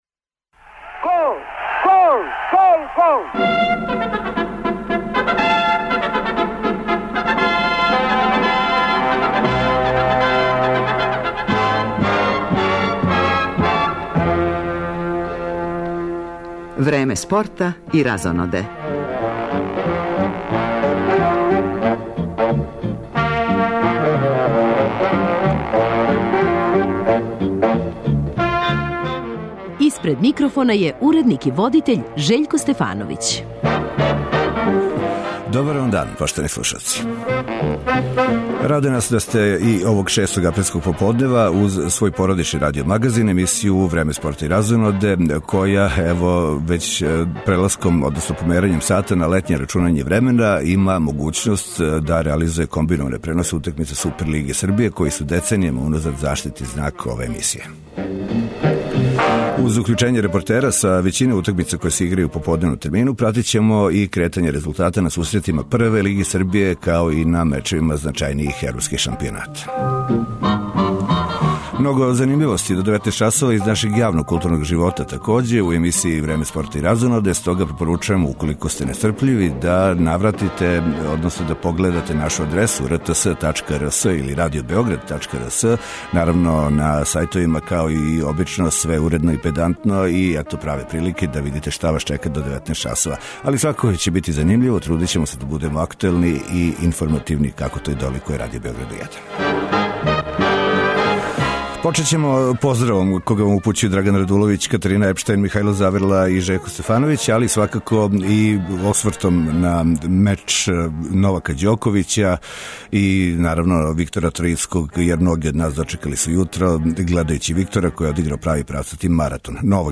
Померањем сата на летње рачунање времена,створиле су се и могућности за реализацију комбинованих преноса утакмица Супер лиге Србије, који су - деценијама уназад - заштитни знак ове емисије. Уз укључење репортера са свих утакмица које се играју у поподневном термину,пратимо и кретање резултата на сусретима значајнијих европских шампионата.